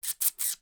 • Hamster Calls
To add to the player’s interest in controlling the hamster, I recorded a series of hamster calls using a human voice and set them to play randomly when the hamster hit the wall in each room.
Hamster_Hiss_5-1.wav